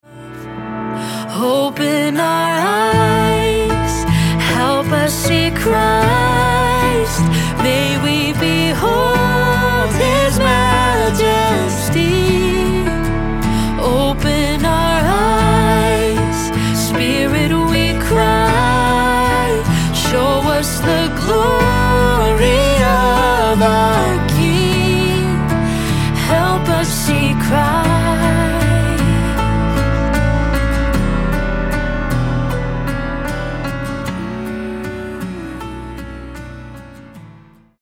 Guitar Chart - Recorded Key (A)